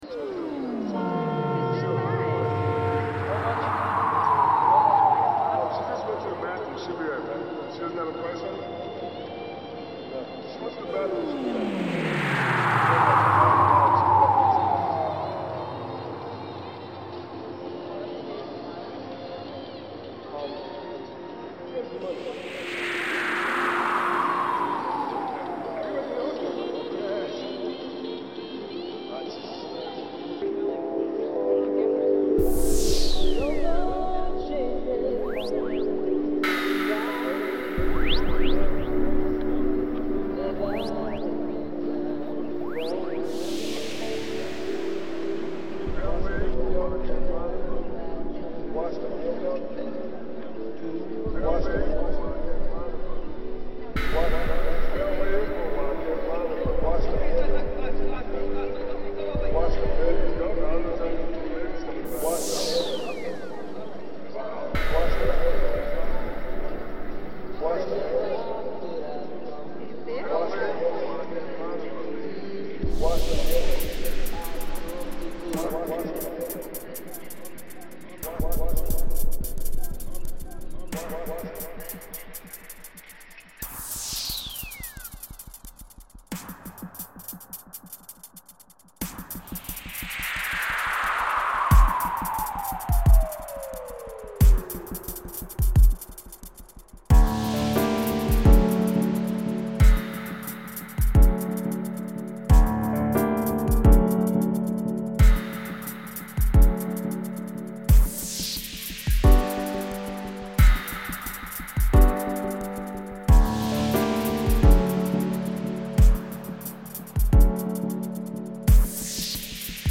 Soho flea market reimagined